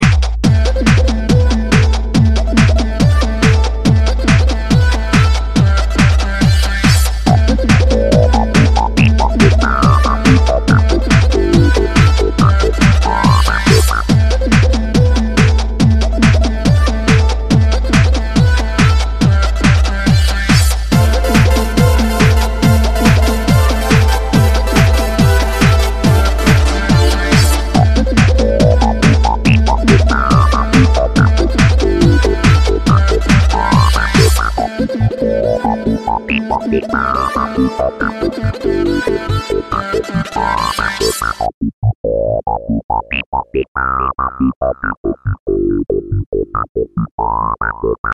• Качество: 128, Stereo
Electronic